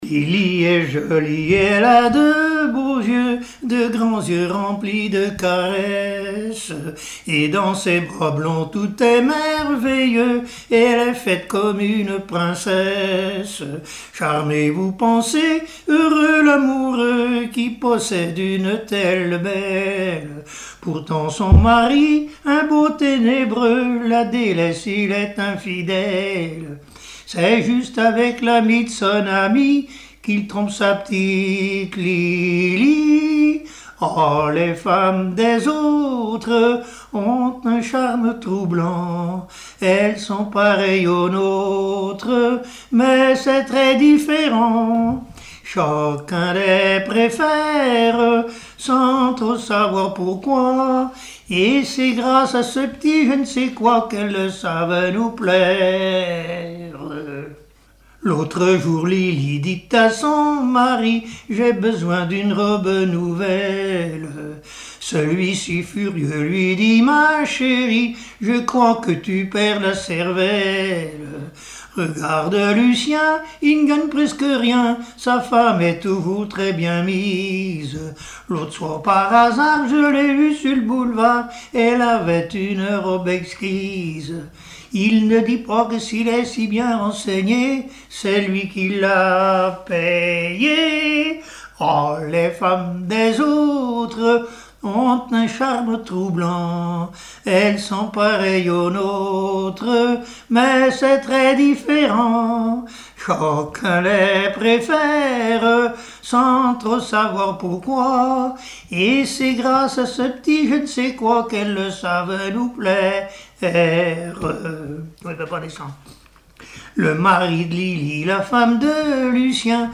Genre strophique
Airs gavotés et histoires drôles
Pièce musicale inédite